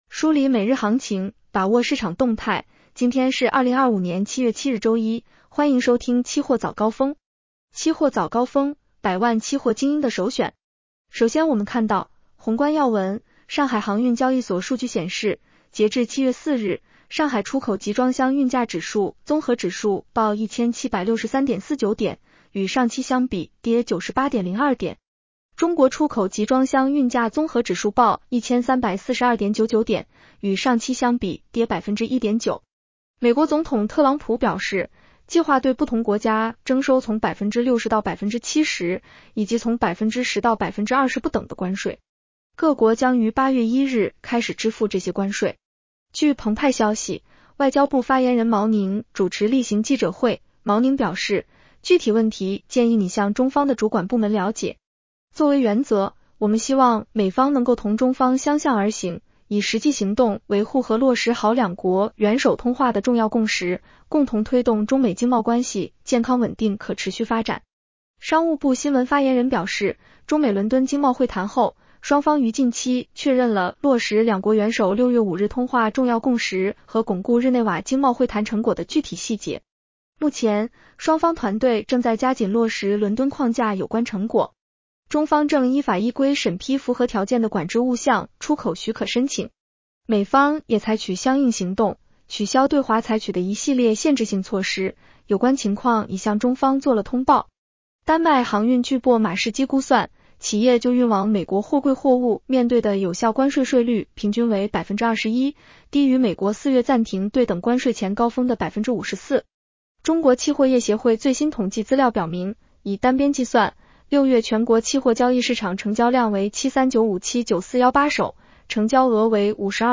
期货早高峰-音频版
期货早高峰-音频版 女声普通话版 下载mp3 宏观要闻 1.上海航运交易所数据显示，截至7月4日，上海出口集装箱运价指数（综合指数）报1763.49点， 与上期相比跌98.02点 。